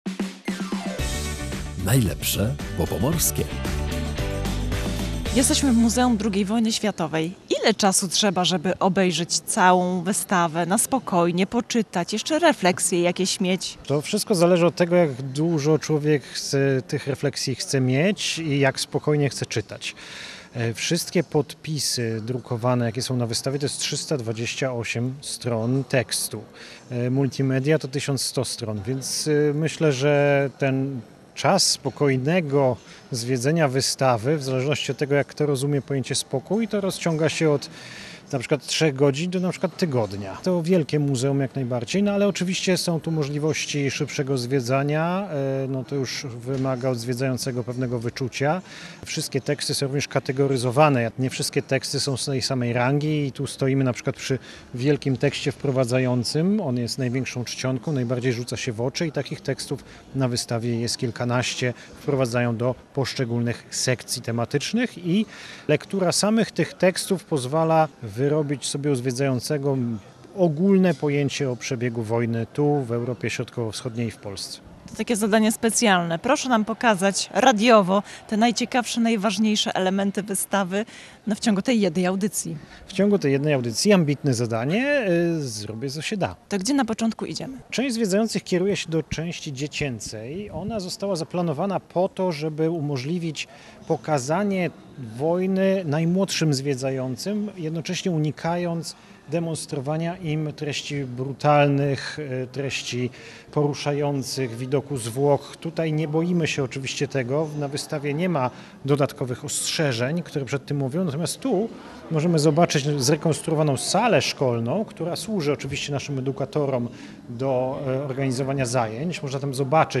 W audycji „Najlepsze bo pomorskie” odwiedziliśmy Muzeum II Wojny Światowej w Gdańsku.